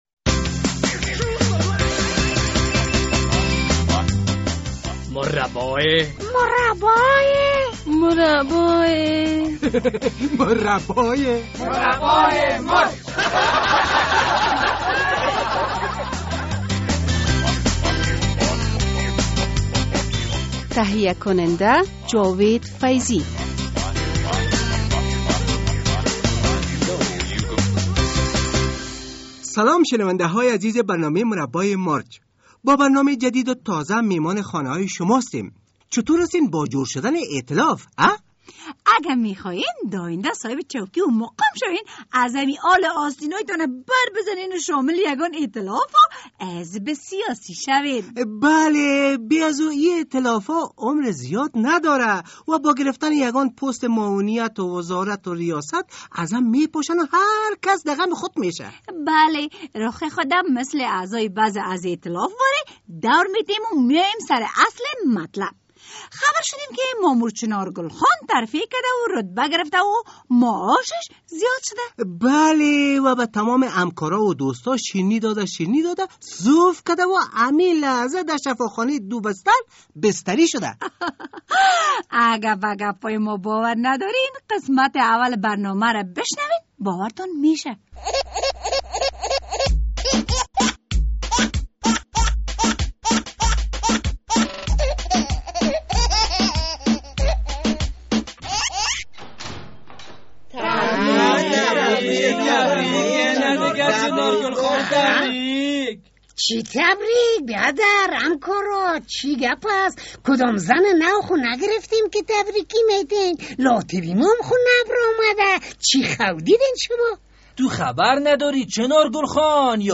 مشاعرهء شاعران انجمن پنسل